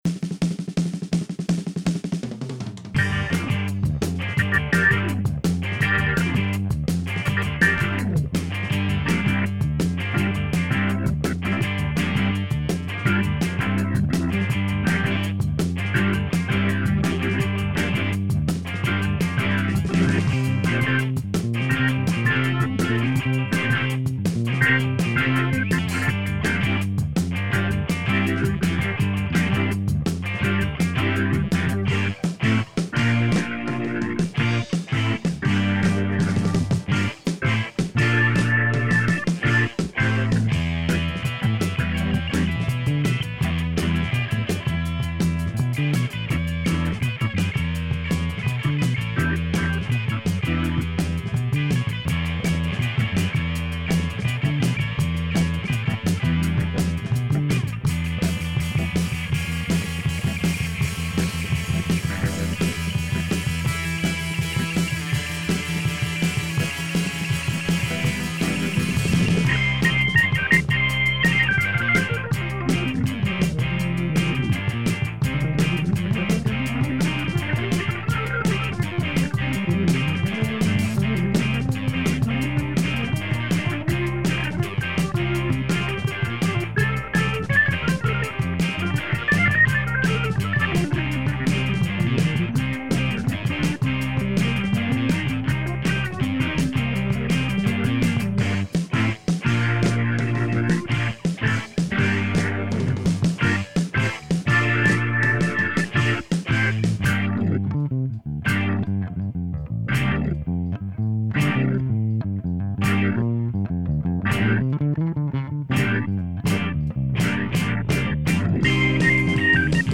ça a un petit côté 60ties bien plaisant à mes oreilles ;)